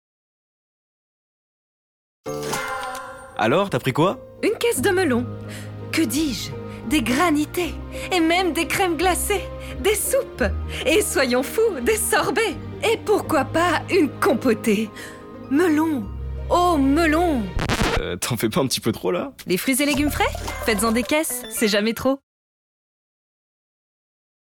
Voix off
Spot Radio Interfel Melon
25 - 45 ans - Mezzo-soprano